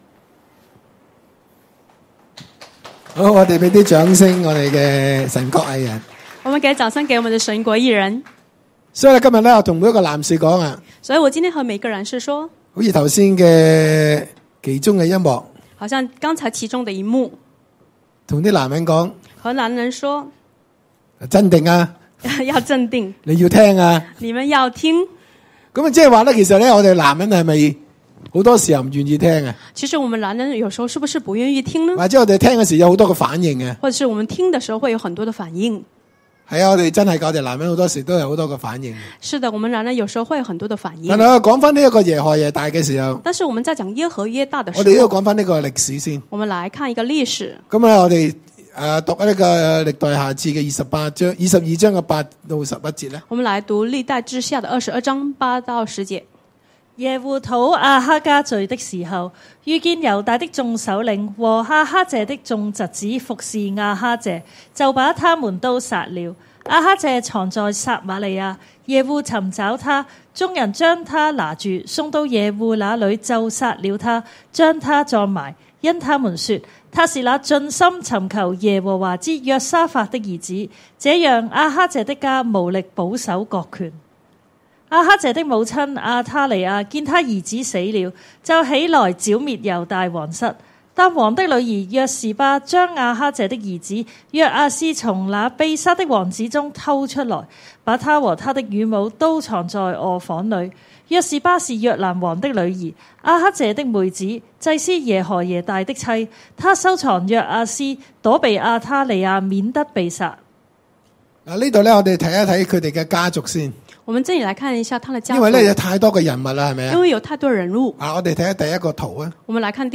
下載 主日崇拜信息：男人回家吧系列(二) 承擔!